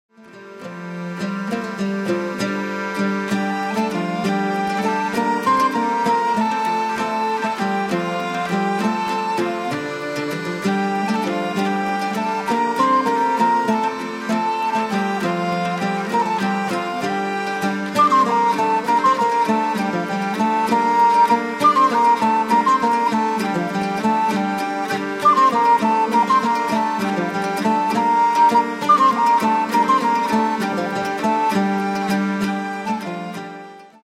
Musiques du XIème au XIIIème siècle
Tous les instruments.